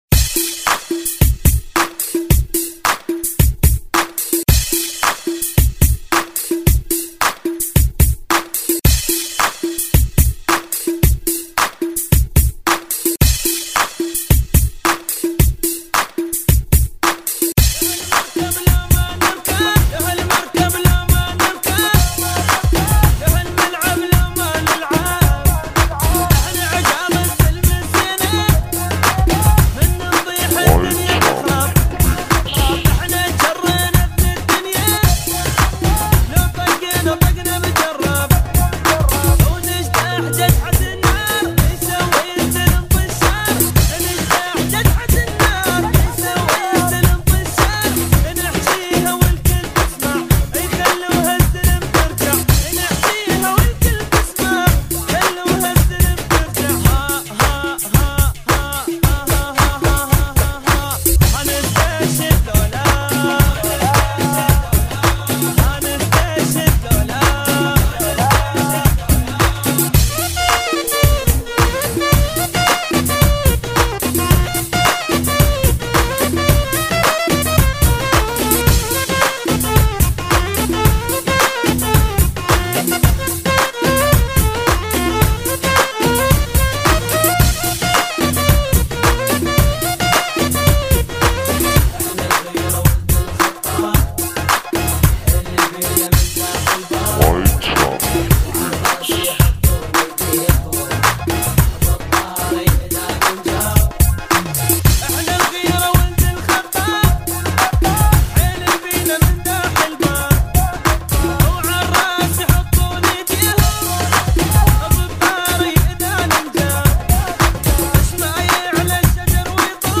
فنكي مكس